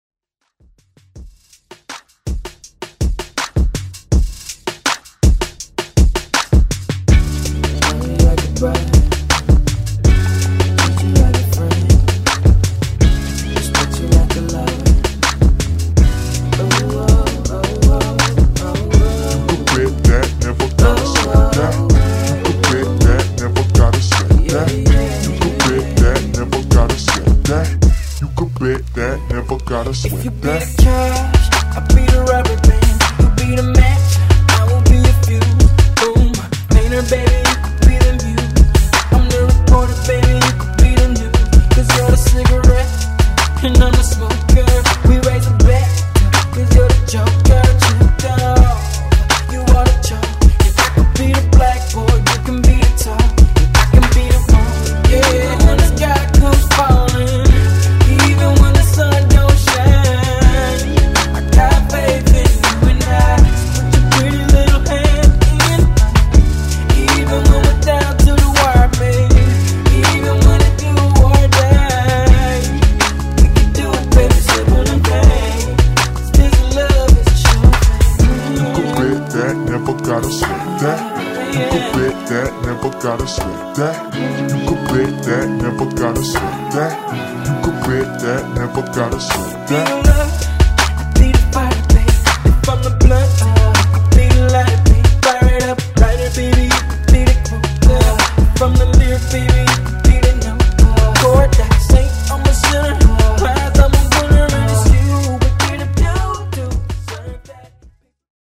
Genre: COUNTRY
Clean BPM: 100 Time